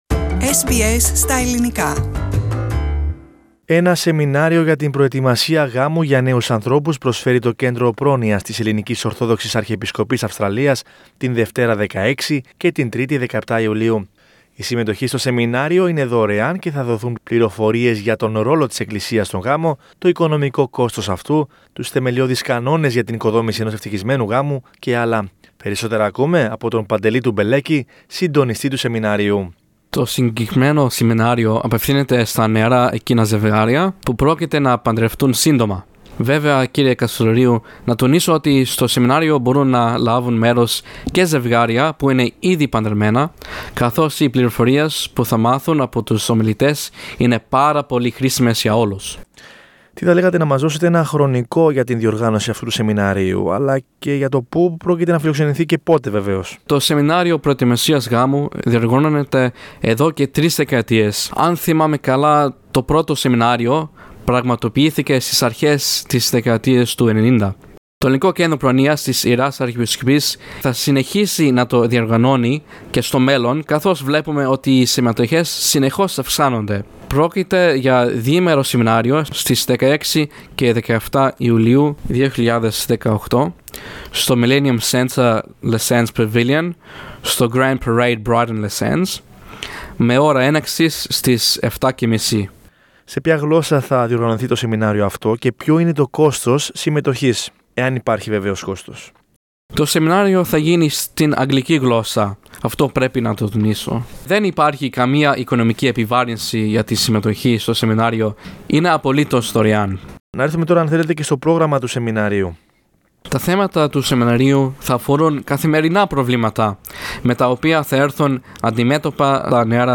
Πατήστε Play στο Podcast που συνοδεύει την κεντρική φωτογραφία για να ακούσετε τη συνέντευξη.